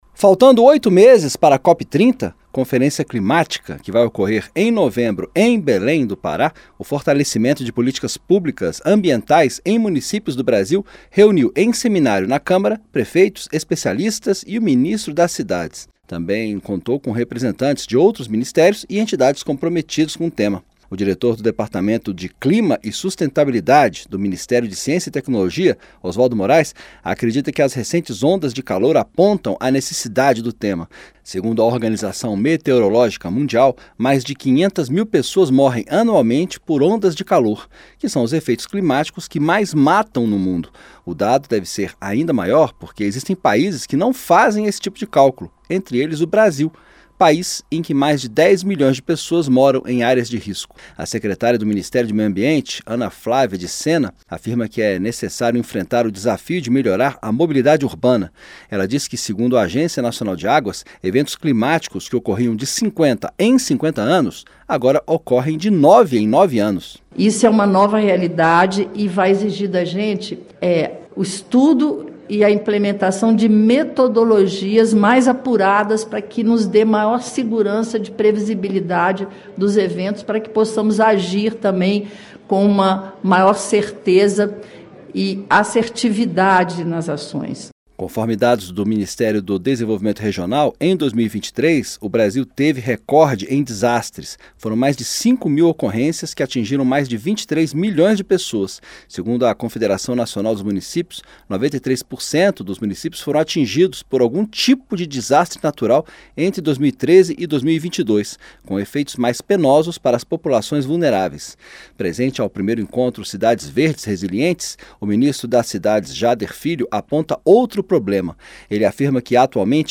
Fortalecimento de políticas ambientais nos municípios reúne especialistas na Câmara - Radioagência